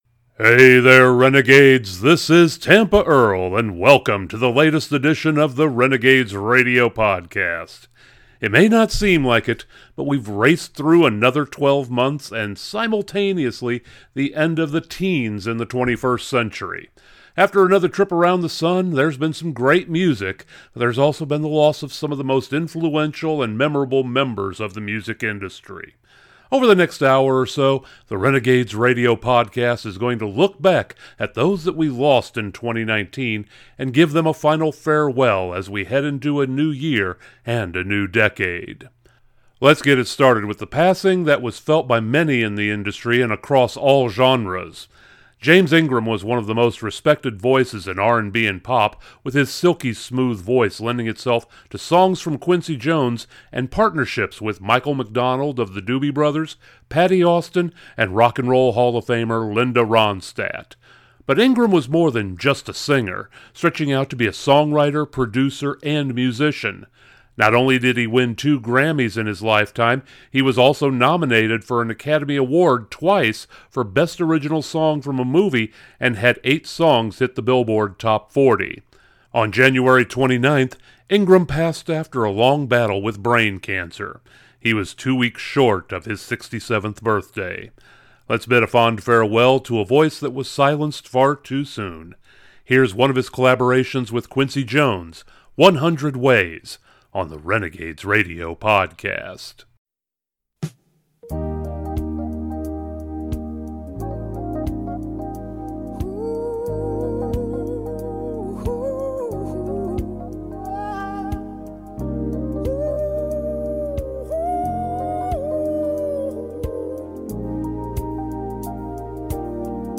The Renegades Radio Podcast is taking time in this episode to salute those that departed this mortal plane in the past year. From some of the greatest musicians in the country ranks to legends of the rock world, there have been some saddening moments. But we can take solace in the fact that they left their musical legacies for us to enjoy and we will do that on this episode of the Renegades Radio Podcast!